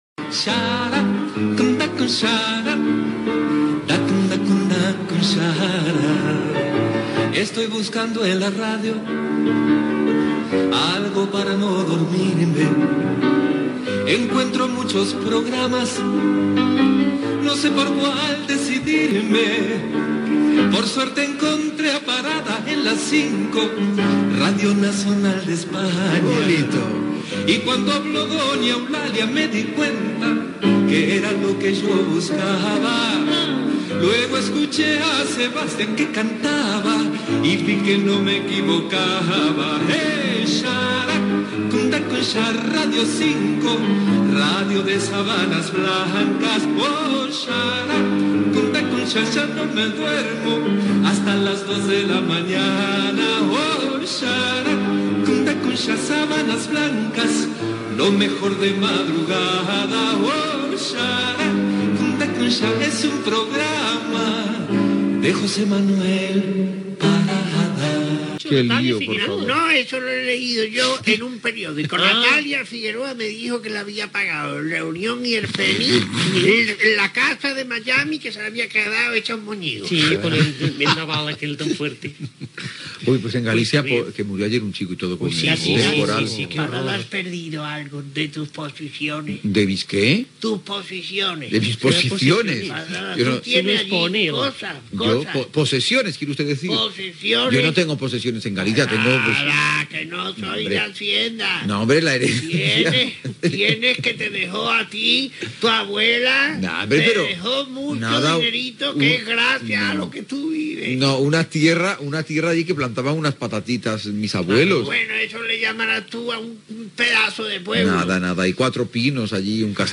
Cançó del programa, comentari sobre Galícia i sobre que el cantant Raphael interpretarà a Calígula en el teatre
Entreteniment